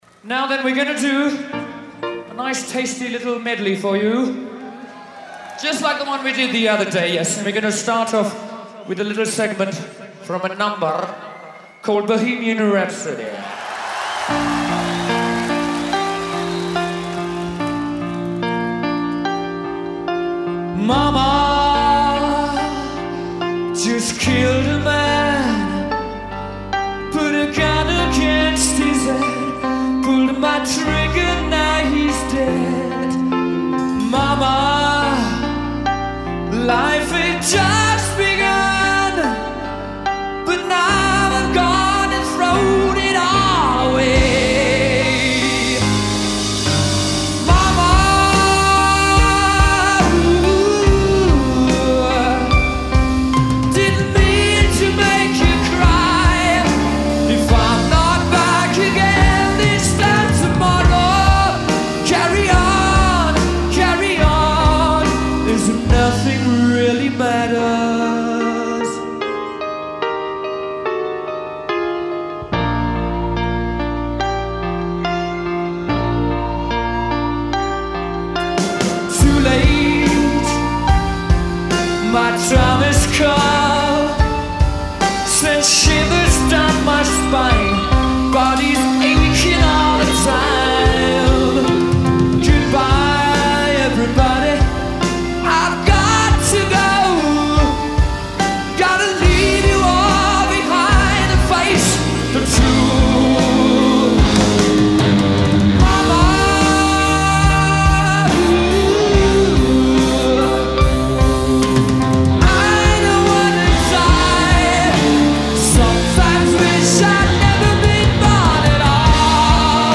The regal rock